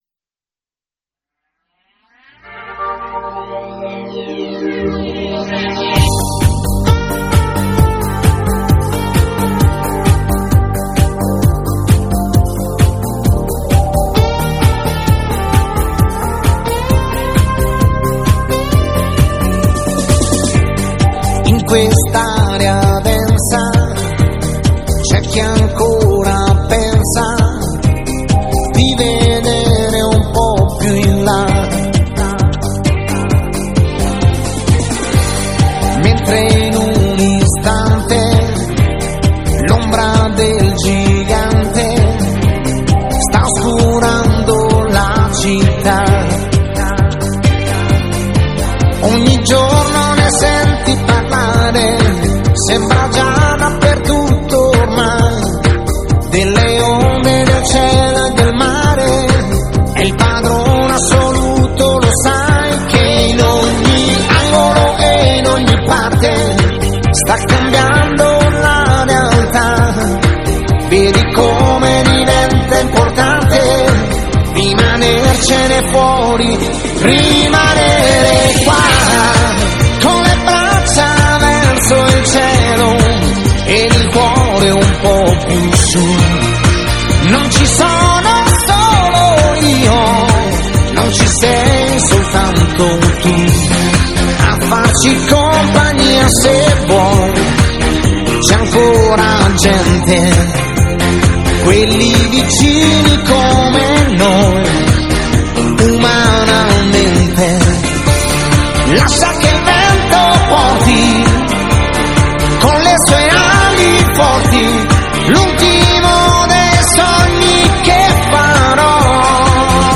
Pop Rock, Latin Pop